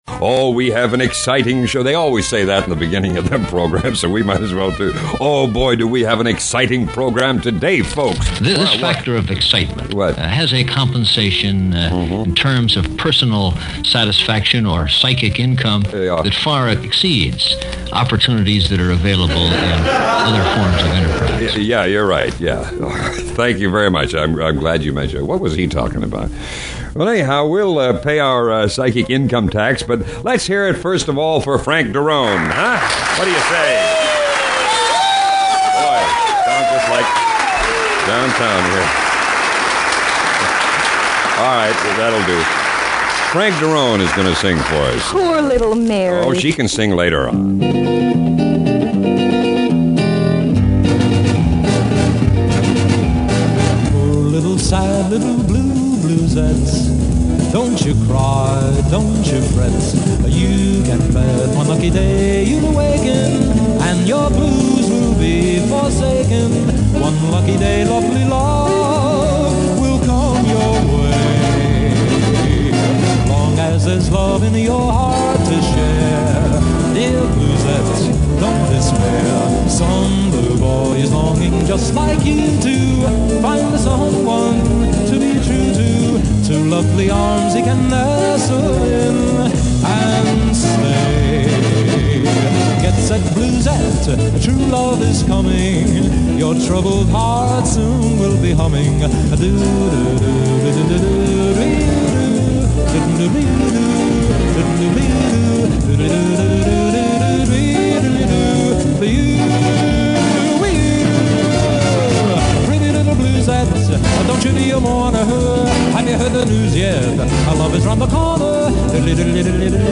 WTAE-AM-Pittsburgh-Rege-Cordic-Company-1969.mp3